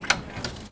R - Foley 58.wav